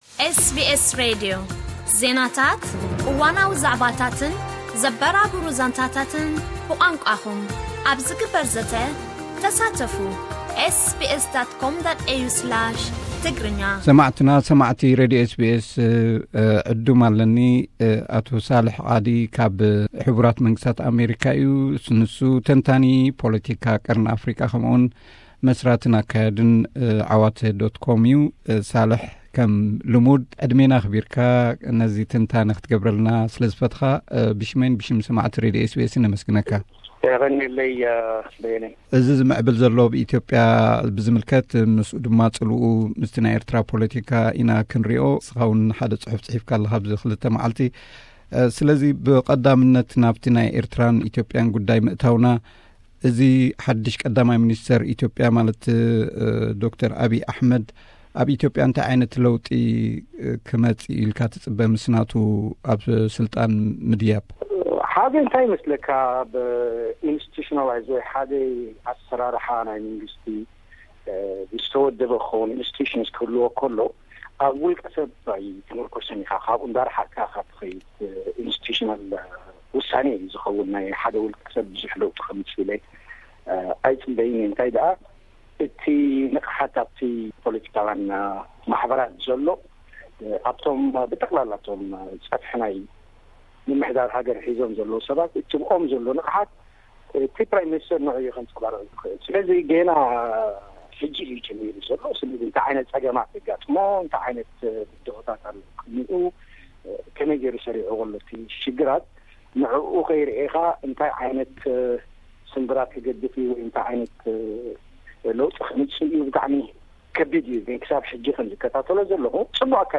ቃለ መሕትት